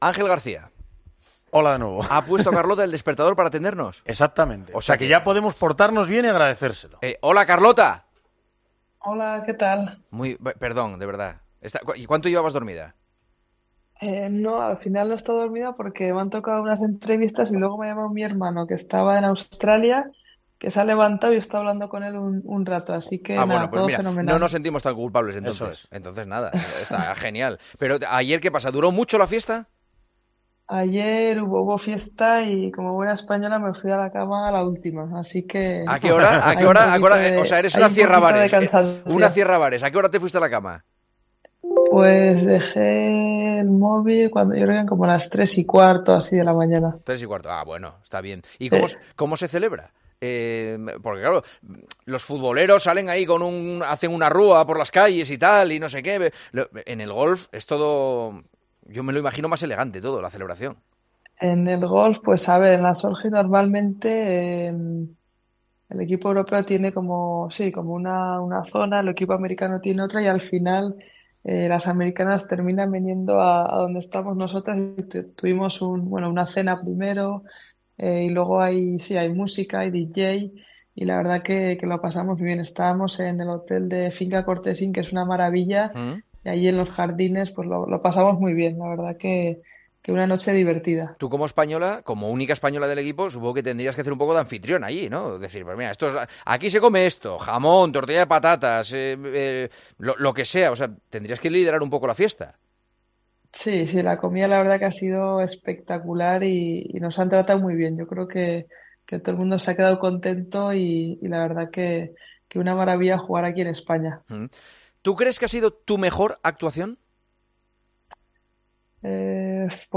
Carlota Ciganda se comprometió con El Partidazo de COPE a poner el despertador para atender la llamada de Juanma Castaño, pero no hizo falta.